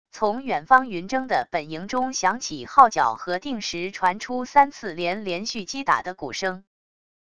从远方云峥的本营中响起号角和定时传出三次连连续击打的鼓声wav音频